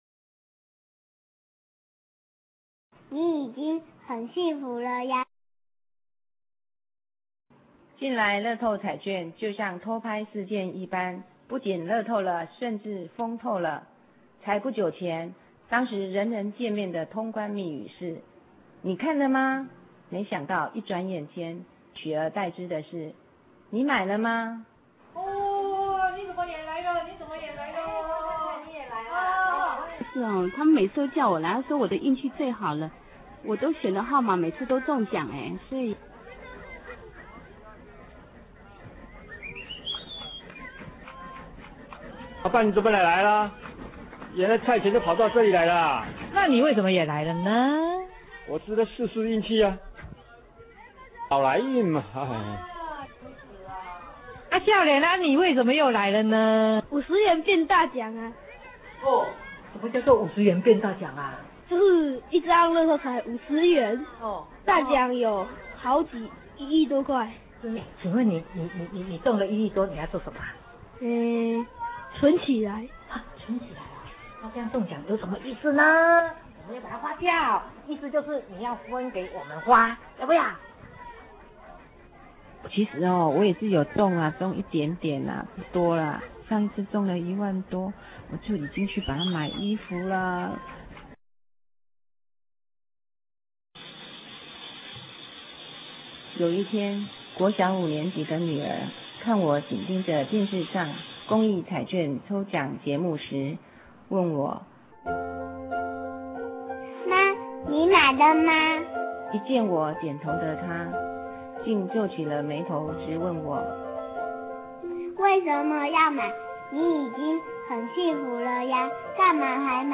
布偶劇︰你已經很幸福了